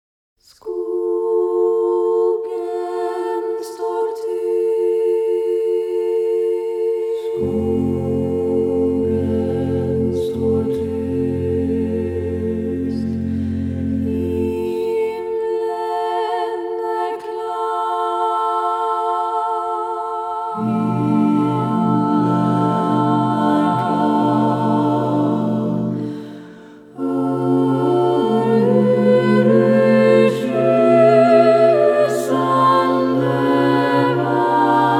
# Choral